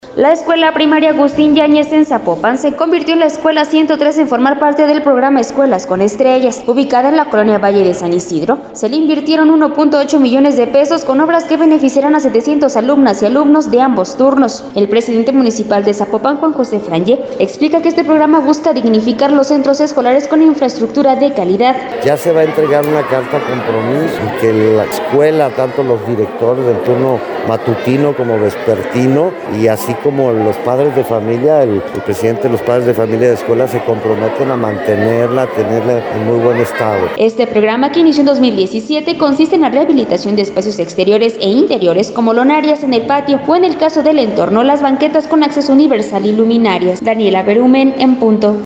El Presidente Municipal de Zapopan, Juan José Frangie, explica que este programa busca dignificar los centros escolares, con infraestructura de calidad.